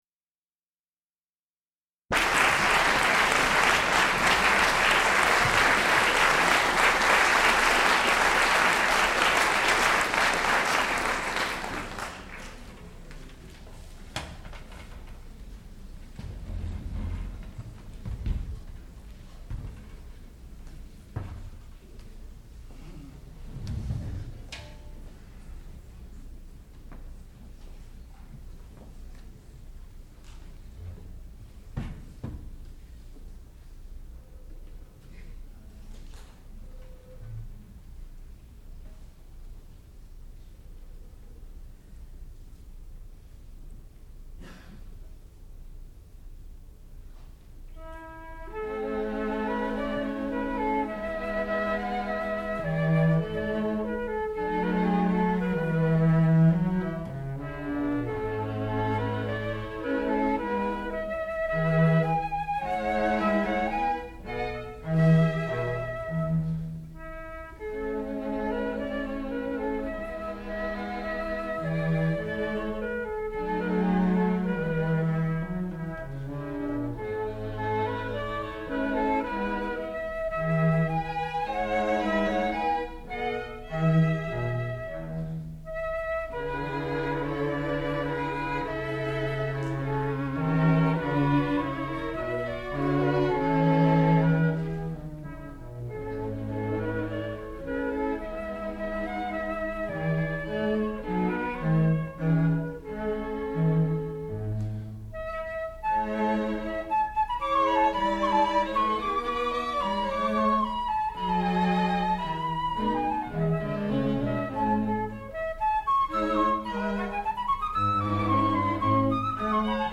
sound recording-musical
classical music
The Shepherd Chamber Players (performer).